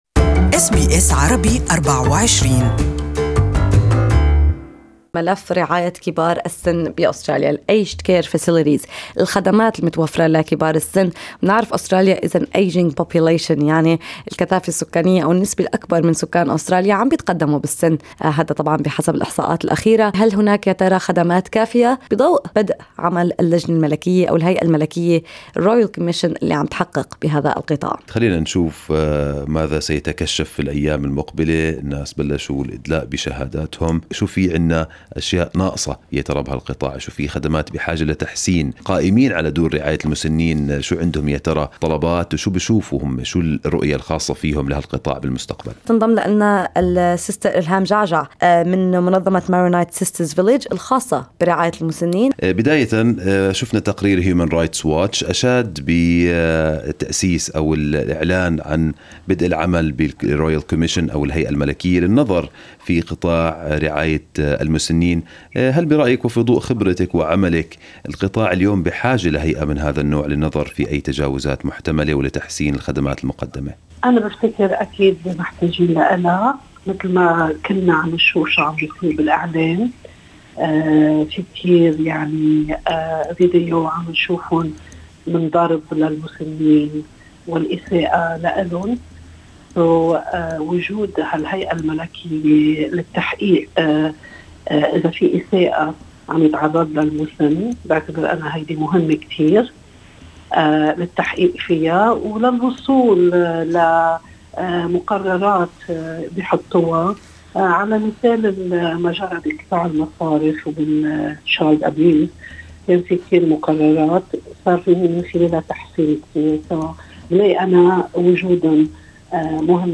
استمعوا الى المقابلة كاملة عبر الضغط على التسجيل الصوتي المرفق بالصورة.